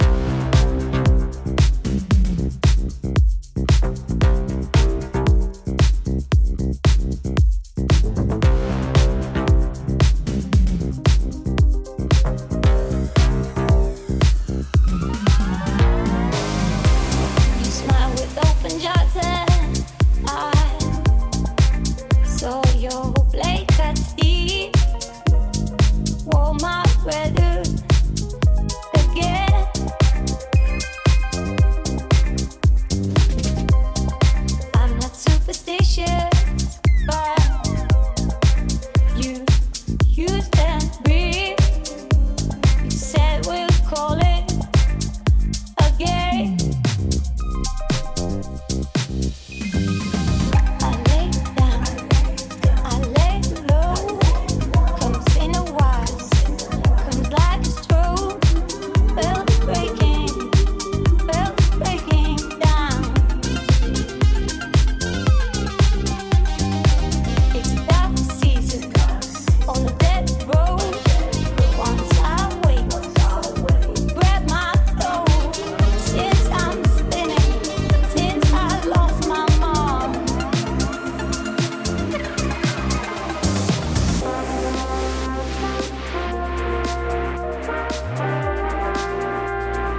ジャンル(スタイル) HOUSE / INDIE DANCE / NU DISCO